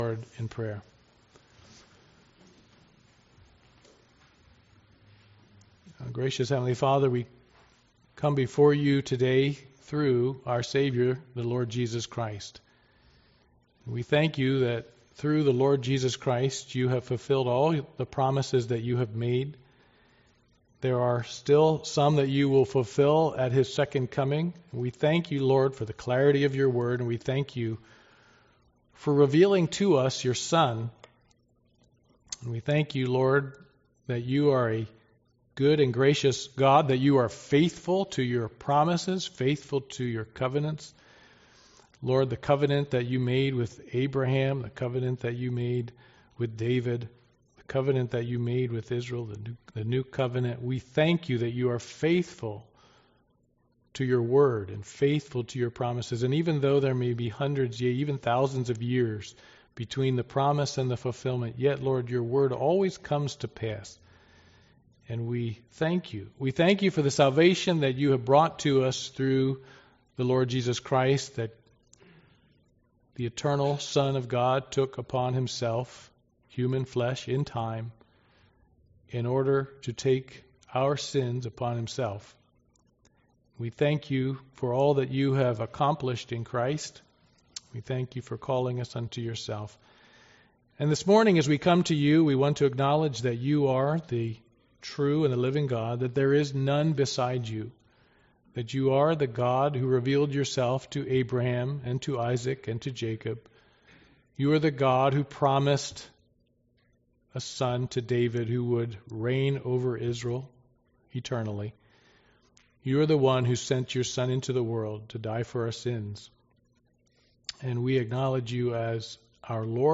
Passage: Philippians 2 Service Type: Sunday Morning Worship